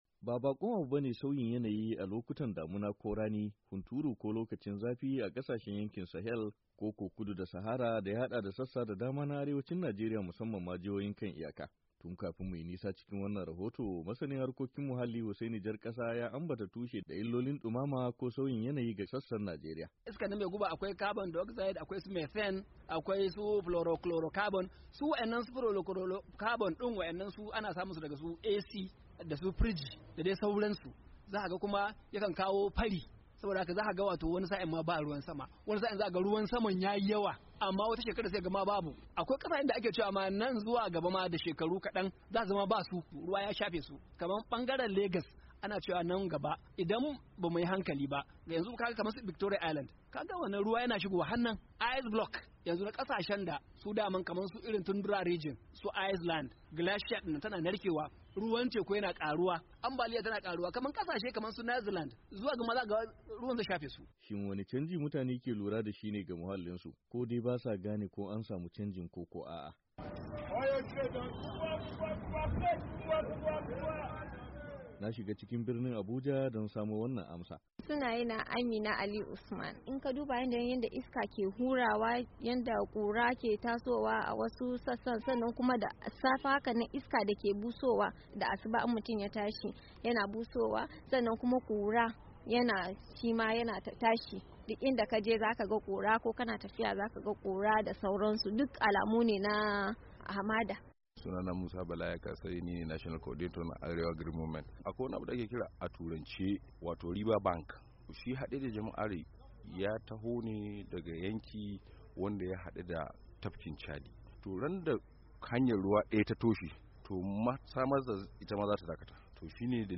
Sauyin Yanayi Na Mummunar Illa - Rahoton